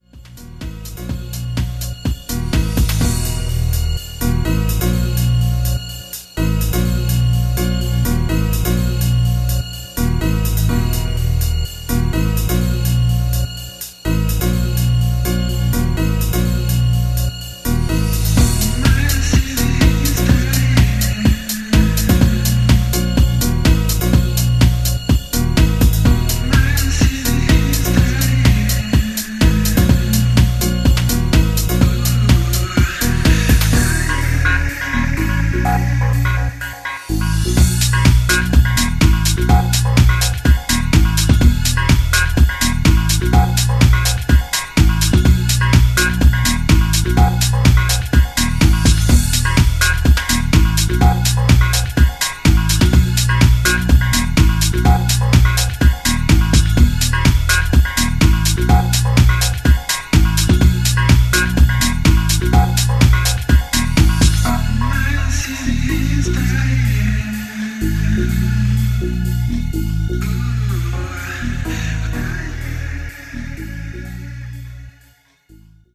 Tracks : 10 House Music Tracks